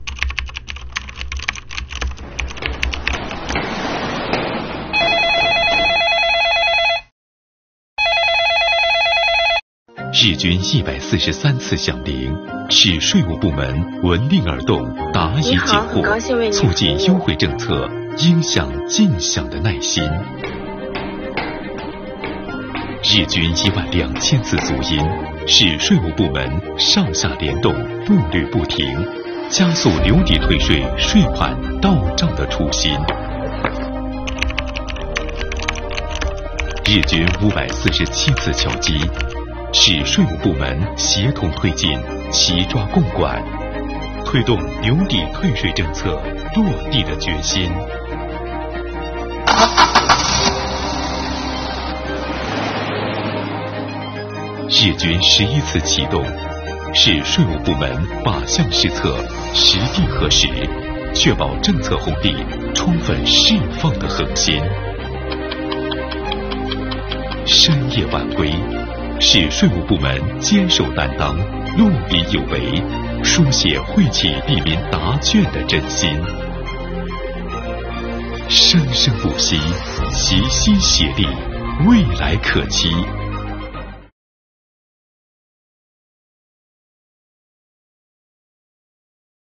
日均143次响铃、12000次足音、547次敲击......每一个声音都是税务部门坚守担当、落笔有为的见证。
作品从声音角度记录了税务部门在留抵退税工作中的付出，从细节处抓取留抵退税工作的瞬间，展示了税务部门“办好实事提质效，用心服务惠民生”的担当与作为。